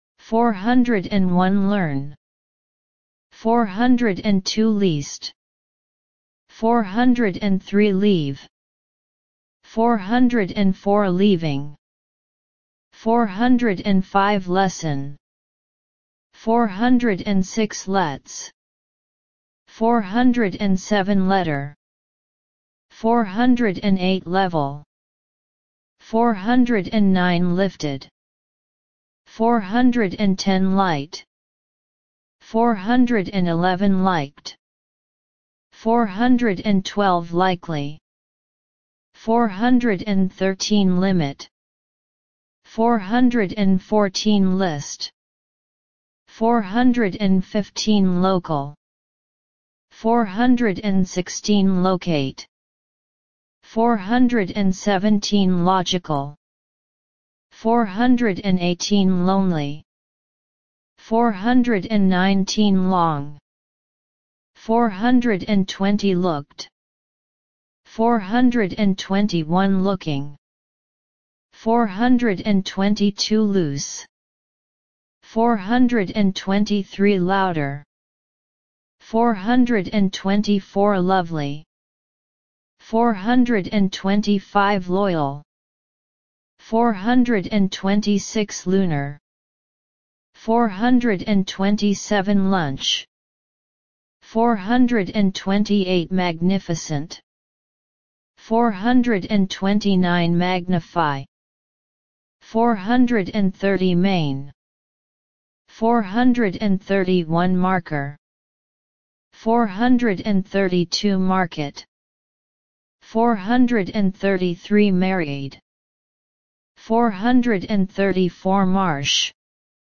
401 – 450 Listen and Repeat